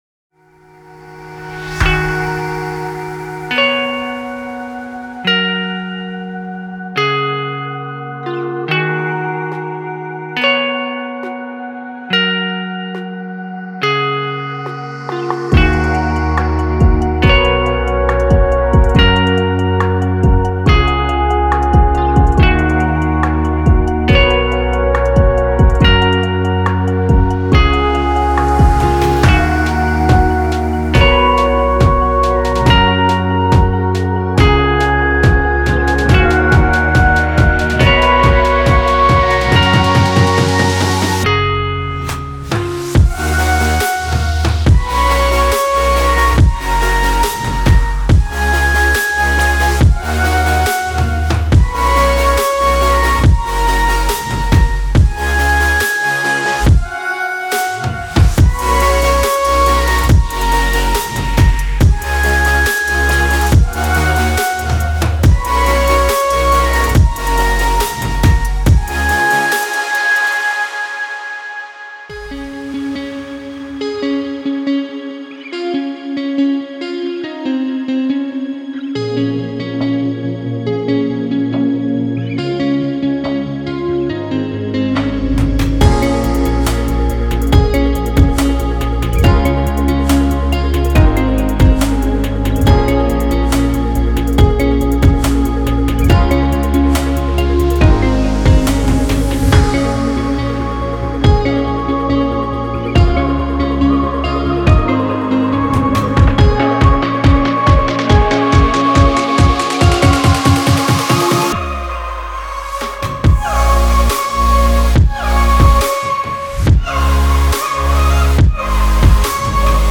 3. Future Bass
巨大的合成器或冰冷的人声可用于您的下一个作品，再也不用担心了。
样本包，包括鼓，FX，人声，吉他及更多